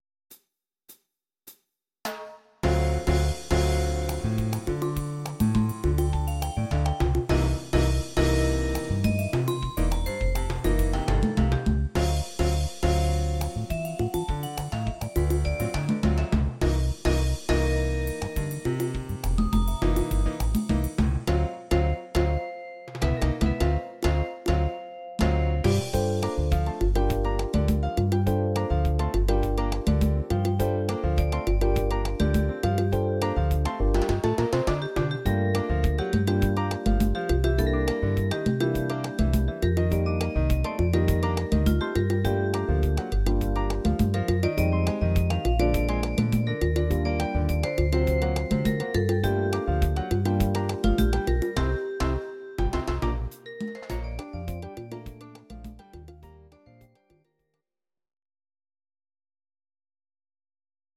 Audio Recordings based on Midi-files
Disco, Musical/Film/TV, Instrumental, 1970s